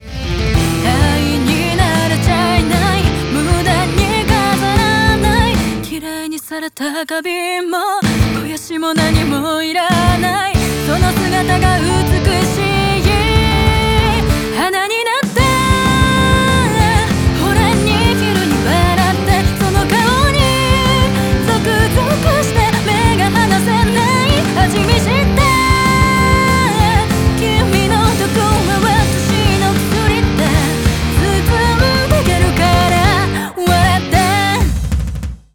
歌声がめちゃくちゃクリアに聴こえるらしいですよ…！！
セルフMIXしている歌い手としては全然『簡易』レベルでは無く高クオリティだと感じました！
素敵な歌声です♪